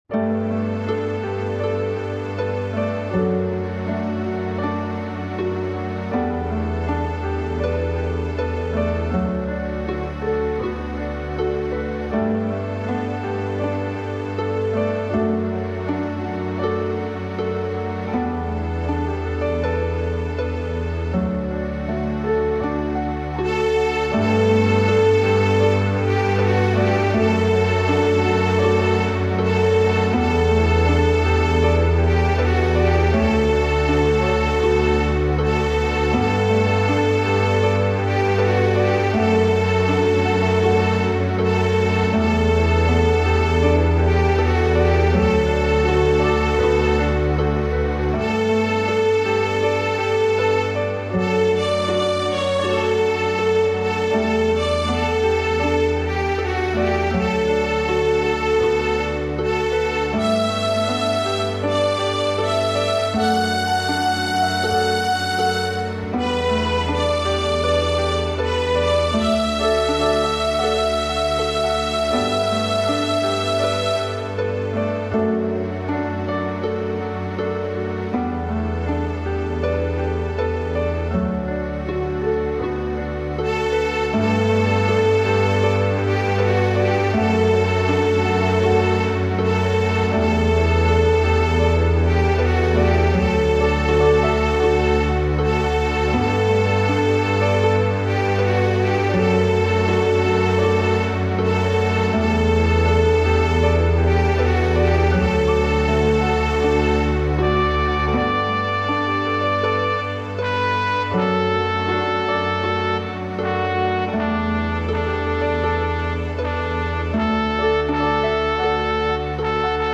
This is a Taizé style song